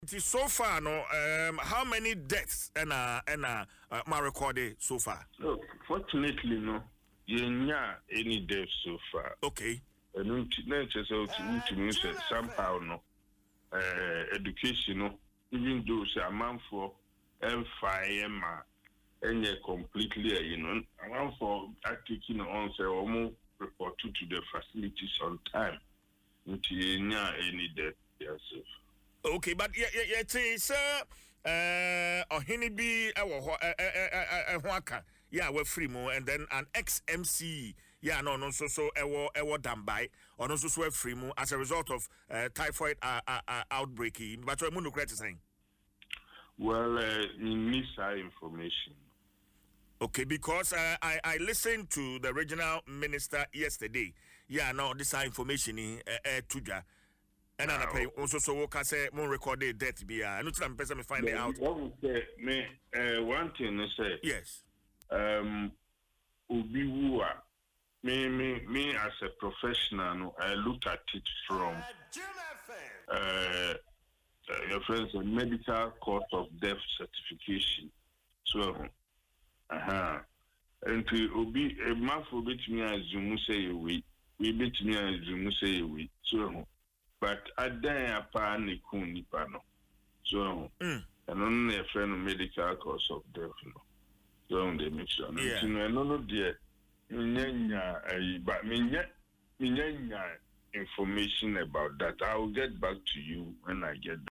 Speaking in an interview on Adom FM’s morning show Dwaso Nsem, Dr. Amo-Kodieh said: